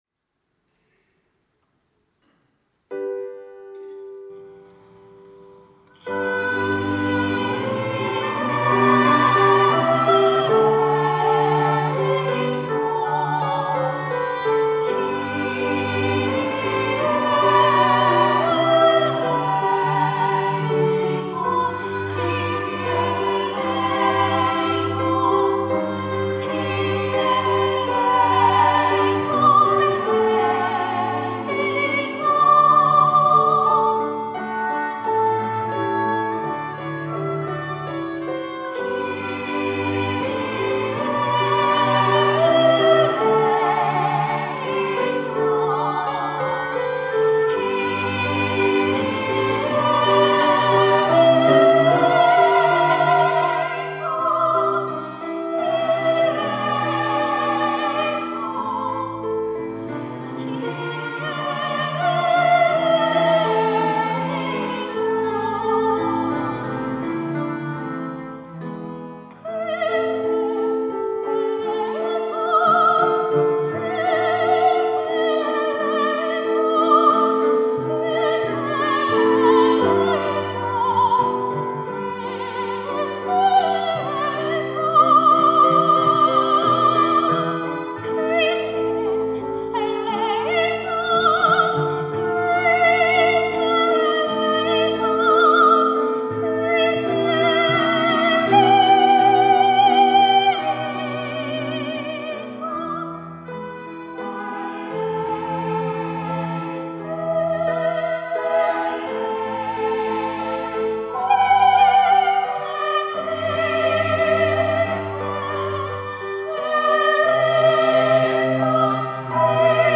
찬양대